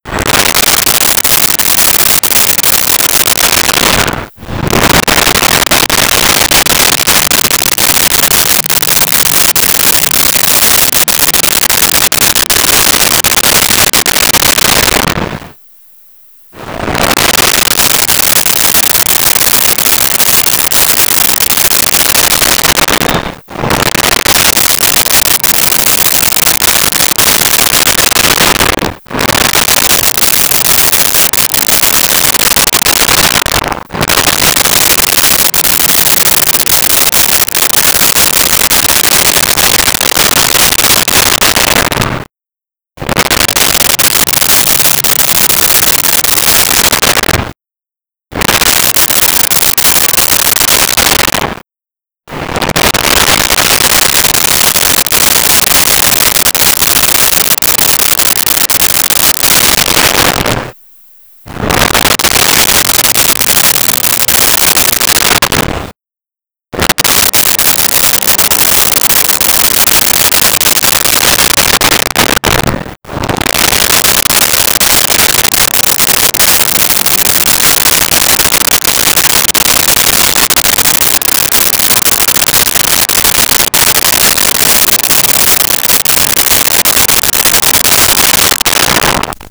Bus Bys.wav